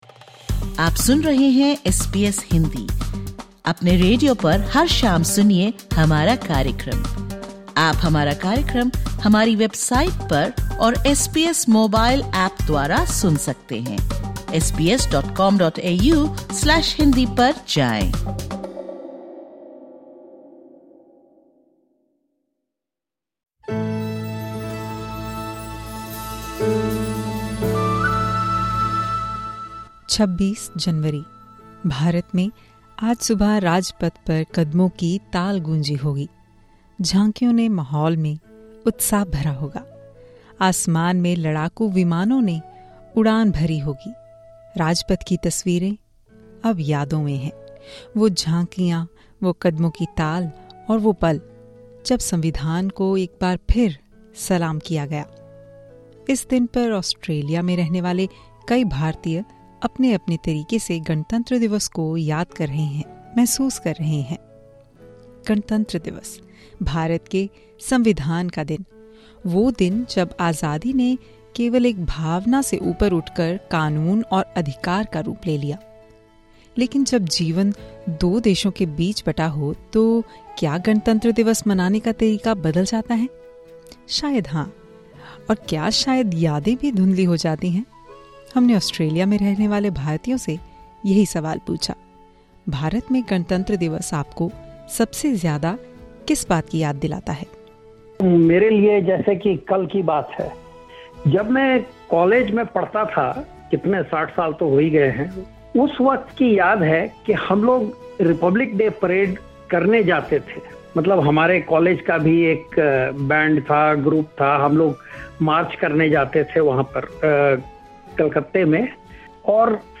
This Republic Day, Australian Indians share their fond memories of this special day and what it means to them in Australia. Prime Minister Anthony Albanese also share his message to community on this occasion.